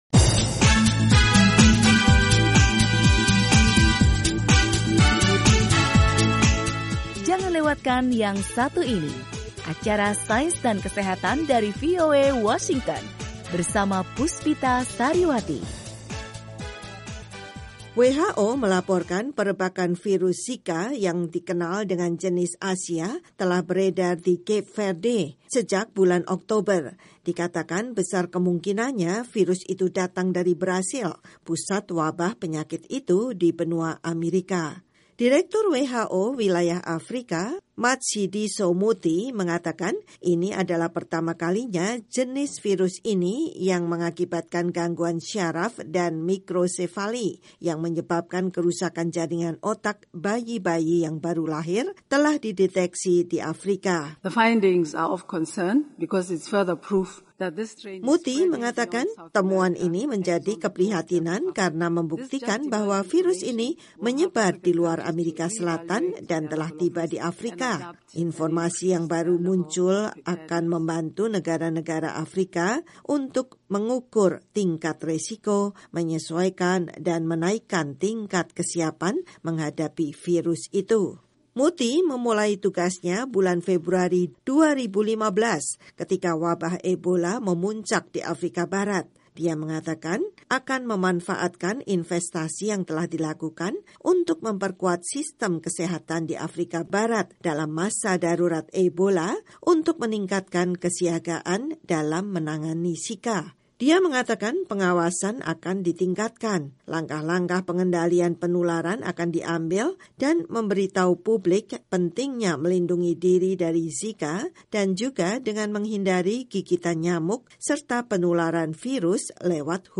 Organisasi Kesehatan Dunia, WHO mengukuhkan virus Zika yang telah merebak di seluruh benua Amerika dan mengakibatkan kelainan otak bayi-bayi yang baru lahir, telah muncul di Cape Verde. Laporan dari markas besar WHO di Jenewa